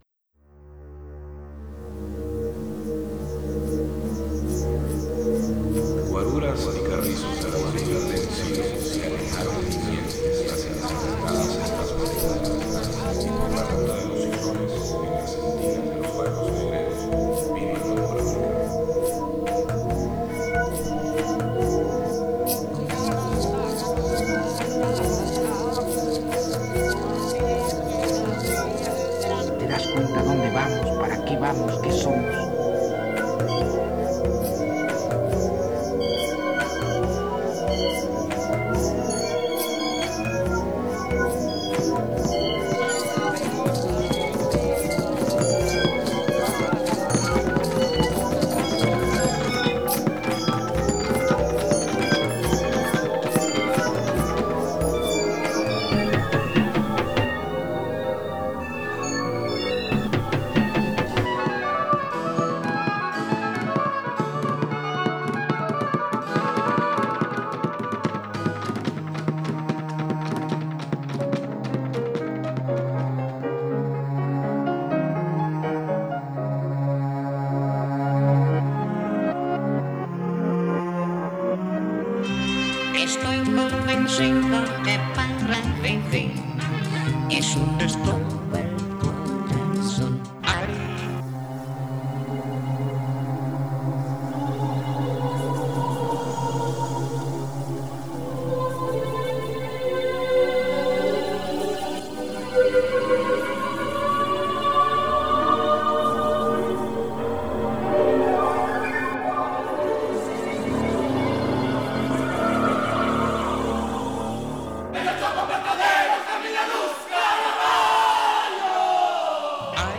Música electroacústica para la instalación de igual nombre realizada con la artista plástica Margot Römer en el año 2003